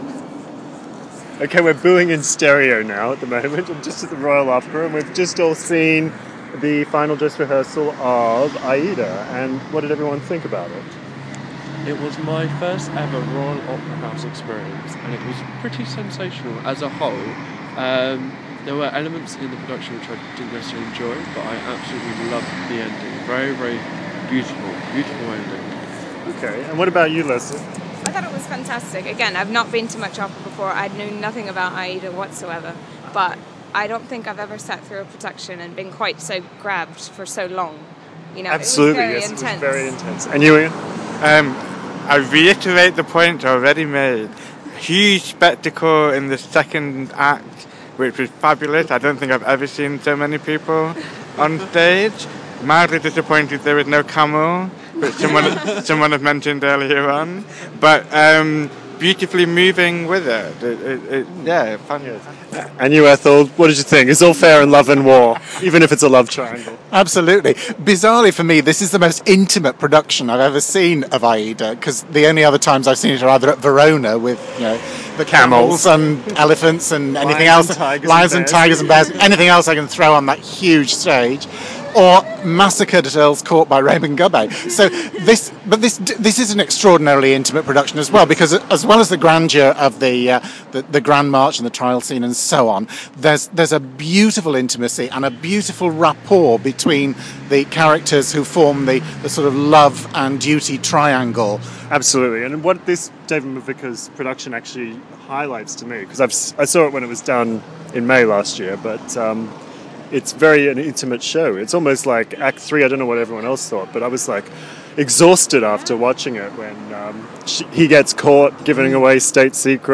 At the opera (final rehearsal): Aida